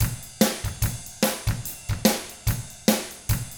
146ROCK T3-L.wav